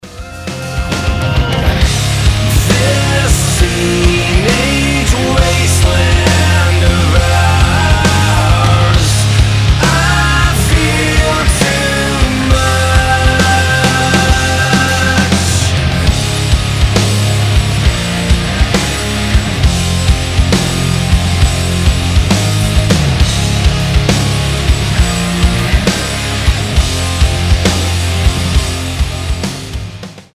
громкие
мощные
грустные
Alternative Rock
post-grunge